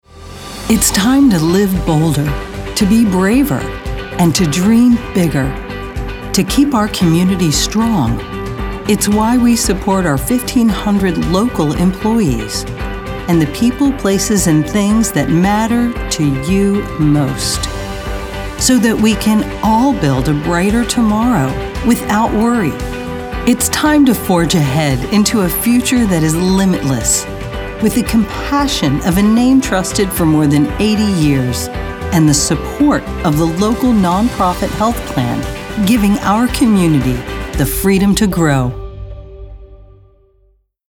announcer, confident, conversational, friendly, genuine, mother, motivational, promo, soccer mom, upbeat, warm